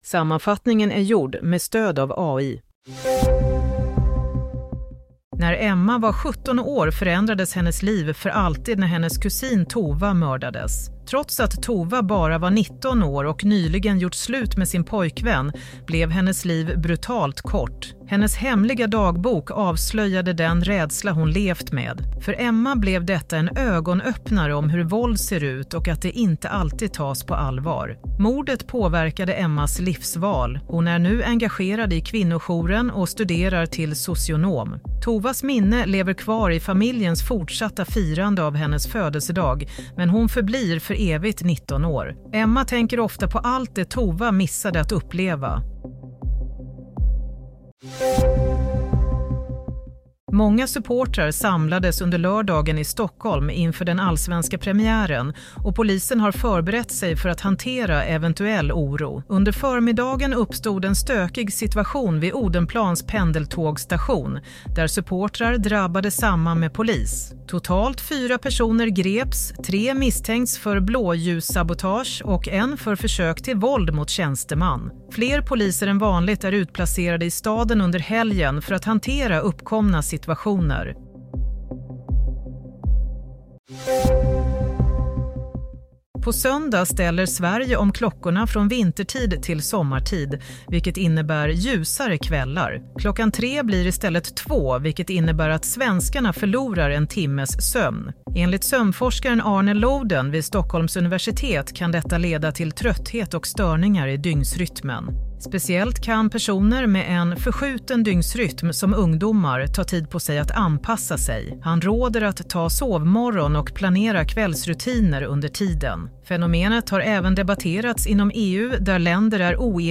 Nyhetssammanfattning - 29 mars 16:00
Sammanfattningen av följande nyheter är gjord med stöd av AI.